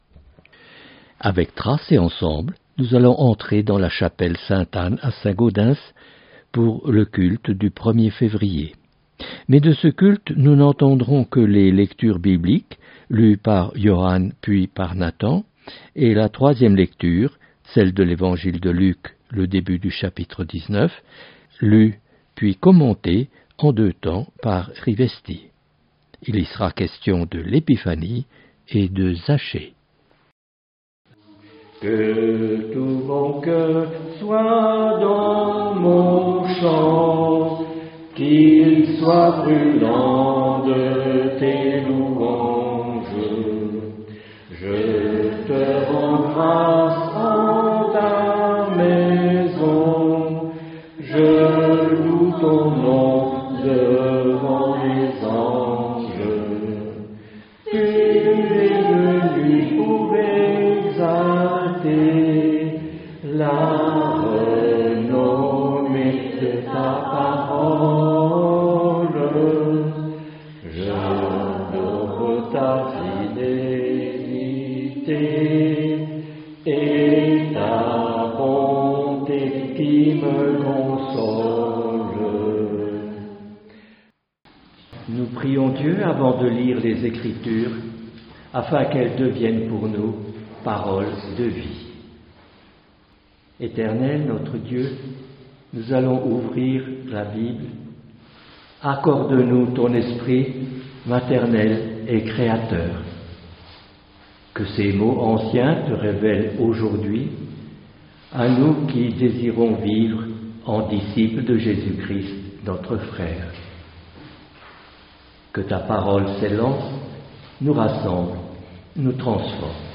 Première partie du culte du 1er février.